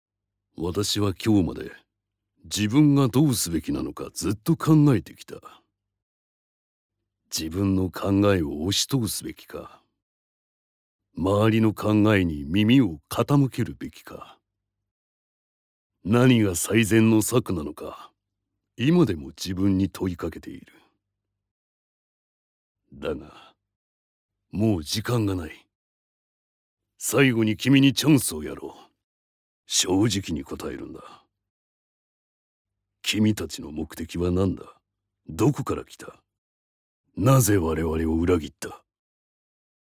My voice is elegant and calmness, and I have extensive experience in “company introductions,” “product introductions,” and “documentaries.
public commercial-style (e.g. TV)
Gentle
Strong
Natural
– Voice Actor –
Stern, thick voice